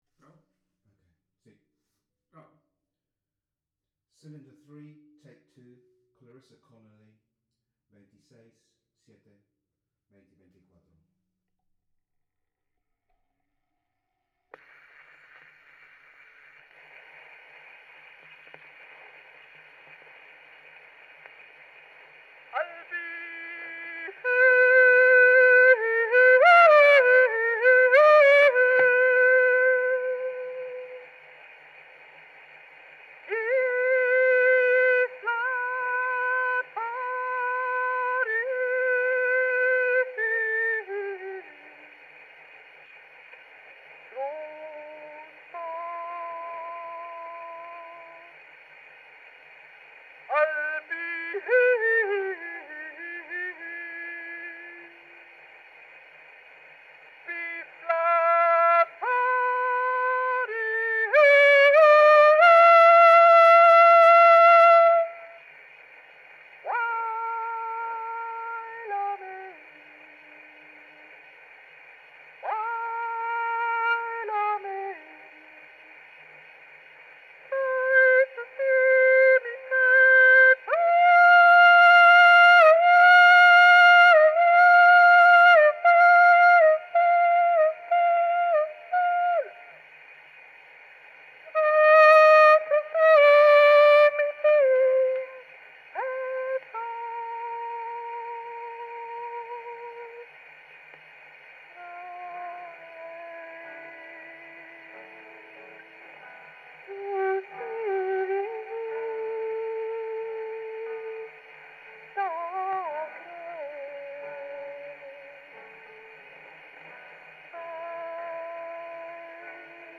Original sound from the phonographic cylinder.